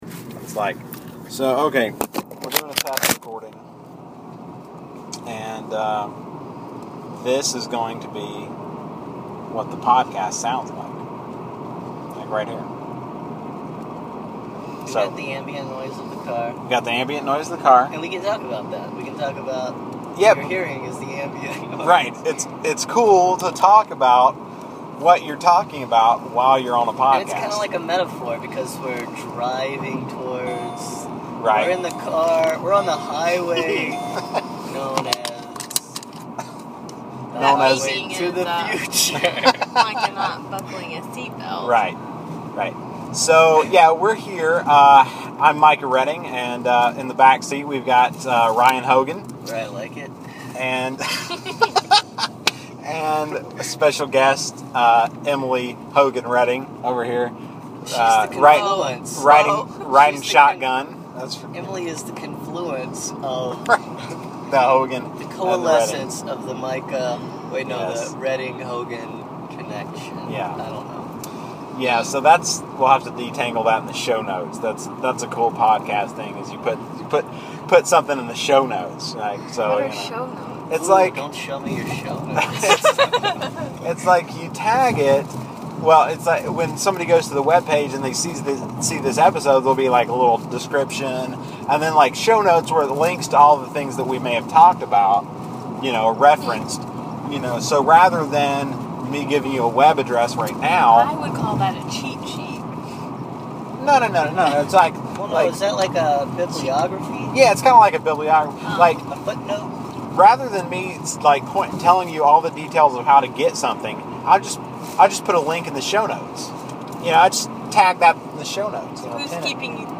Preview Episode 1: Car ride
p01_car_ride.mp3